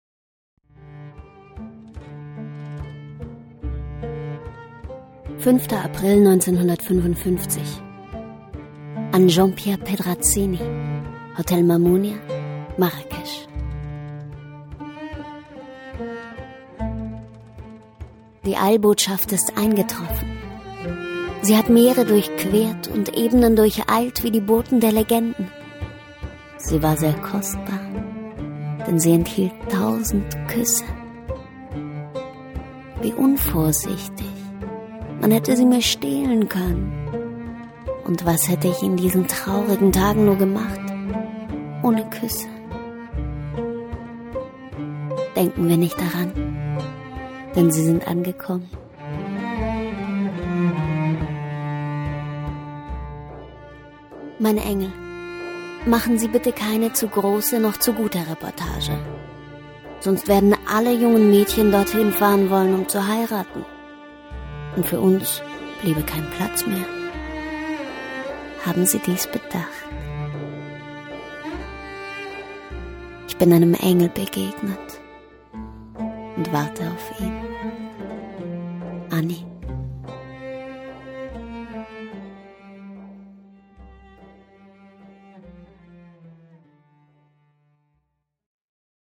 Sprecherin Werbung Stimme Welt Kompakt sachlich seriös sinnlich frech lieblich kindlich derb erotisch Hörbuch Dokumentation Reportage Synchron ausgebildet
Sprechprobe: eLearning (Muttersprache):
professional narrator and voice-over artist with a unique voice and expression